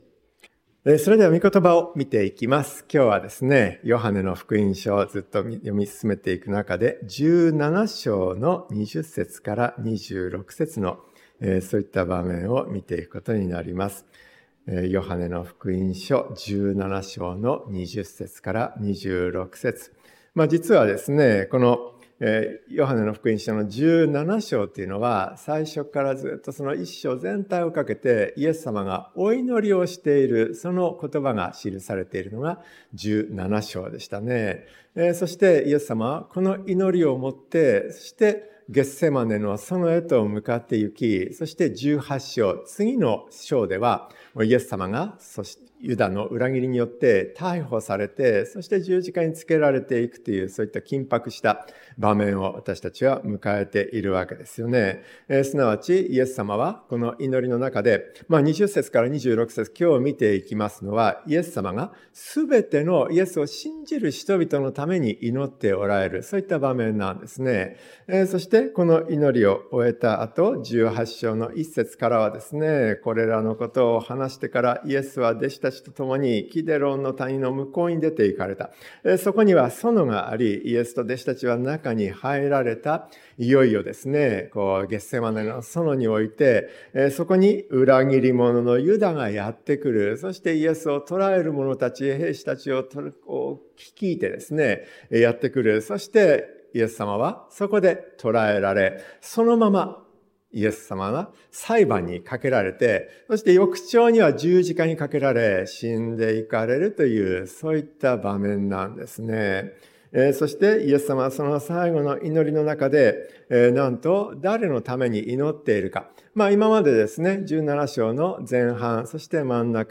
キリストのからだは一つ 説教者